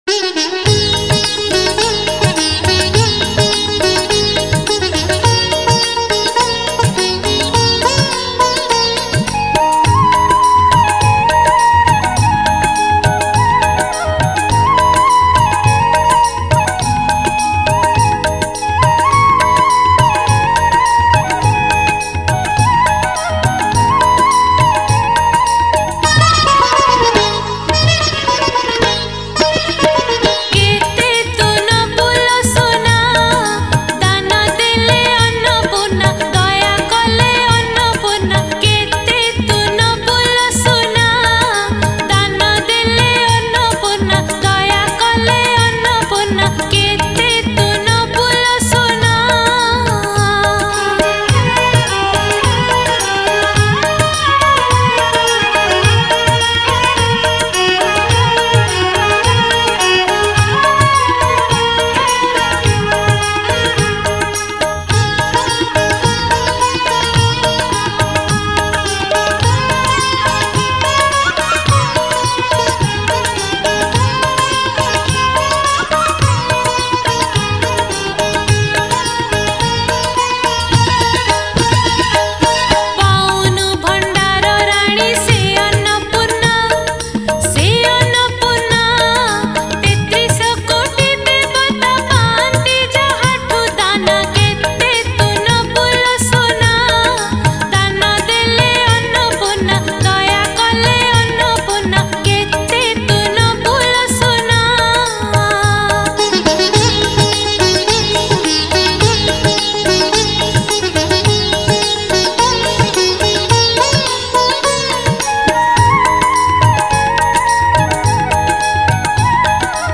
Odia Bhajan Song
Category: Odia Bhakti Hits Songs